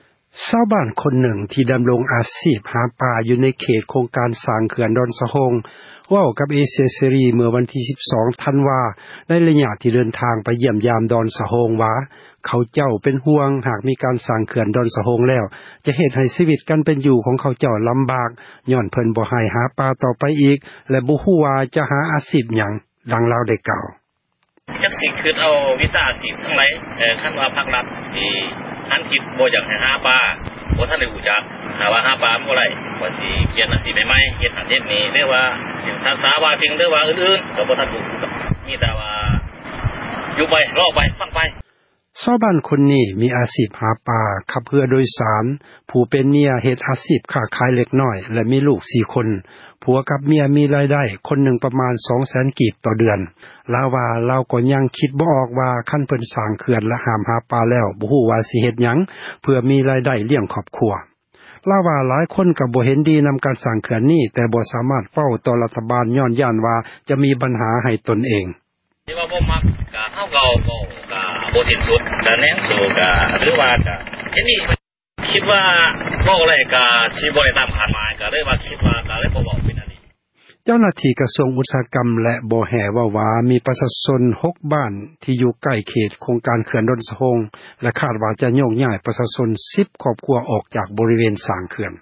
ຊາວບ້ານ ຄົນນຶ່ງ ມີອາຊີພ ຫາປາ ຢູ່ໃນເຂດ ໂຄງການ ສ້າງ ເຂື່ອນ ດອນສະໂຮງ ເວົ້າກັບ ເອເຊັຽ ເສຣີ ເມື່ອວັນ ທີ 12 ທັນວາ ໃນ ຕອນທີ່ ເດີນທາງໄປ ຢ້ຽມຢາມ ດອນສະໂຮງ ວ່າ ເຂົາເຈົ້າ ເປັນຫ່ວງ ຫາກ ມີການ ເລີ້ມສ້າງ ເຂື່ອນ ດອນສະໂຮງ ແລ້ວ ຈະເຮັດ ໃຫ້ຊີວິດ ຂອງ ເຂົາເຈົ້າ ລໍາບາກ ຍ້ອນເພິ່ນ ບໍ່ໃຫ້ຫາປາ ຕໍ່ໄປອີກ ແລະ ບໍ່ຮູ້ວ່າ ຈະຫາ ອາຊີພ ຫຍັງ. ດັ່ງ ຊາວບ້ານ ເວົ້າວ່າ: